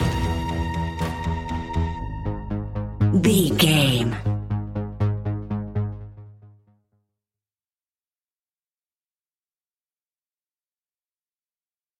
In-crescendo
Aeolian/Minor
ominous
haunting
eerie
instrumentals
horror music
Horror Pads
horror piano
Horror Synths